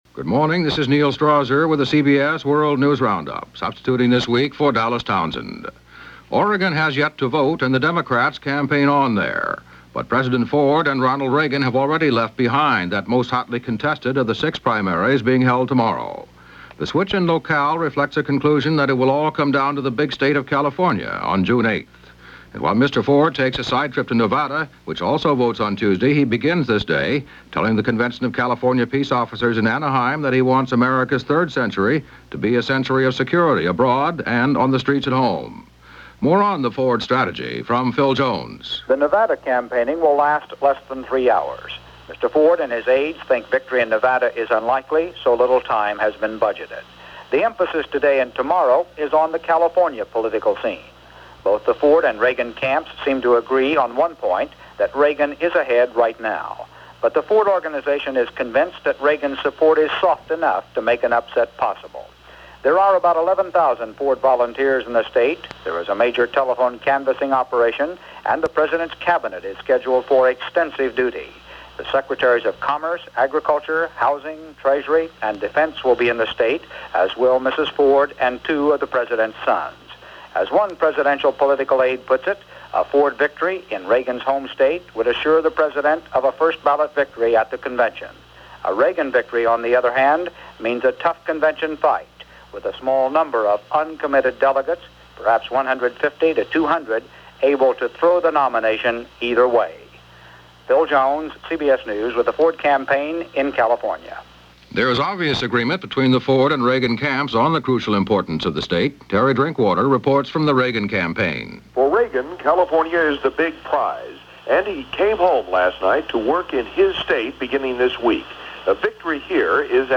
May 24, 1976 – CBS World News Roundup – Gordon Skene Sound Collection –
And that’s a small slice of what went on this May 24, 1976, as reported by the CBS World News Roundup.